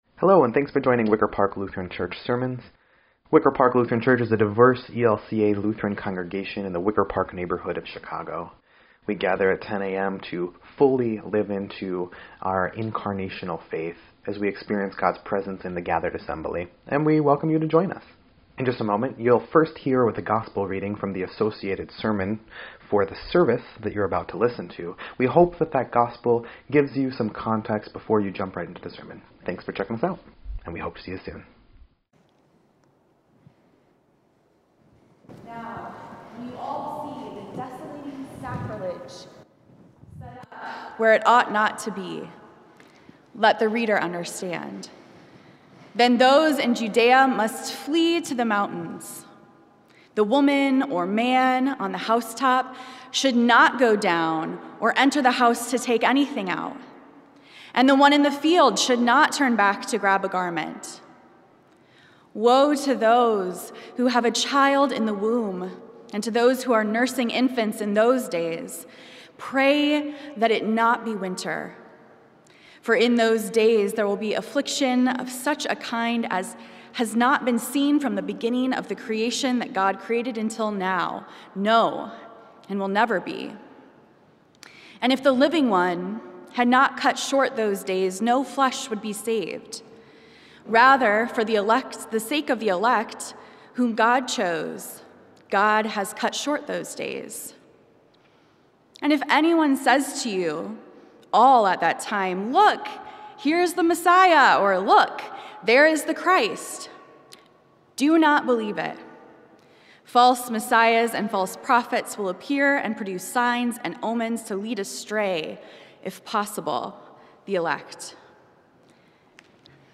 3.20.22-Sermon_EDIT.mp3